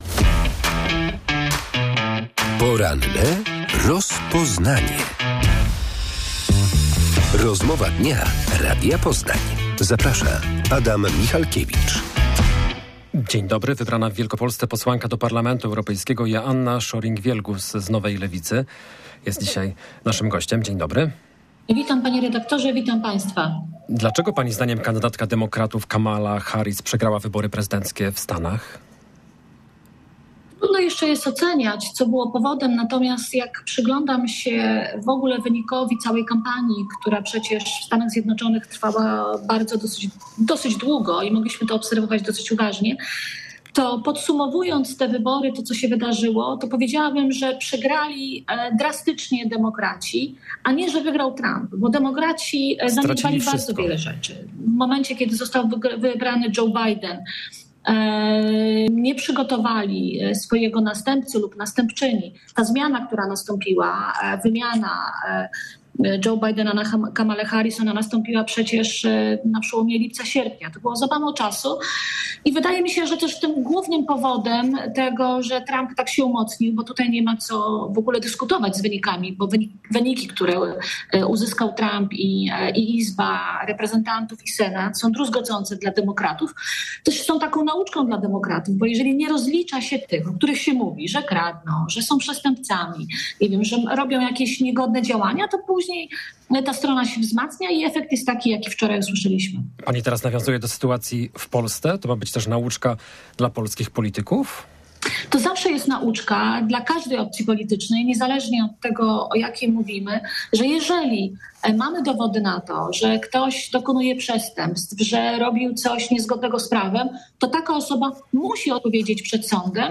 Czy Amerykanie nie chcieli kobiety na stanowisku prezydenta? Gościem porannej rozmowy Radia Poznań była posłanka do Parlamentu Europejskiego Joanna Scheuring-Wielgus z Nowej Lewicy.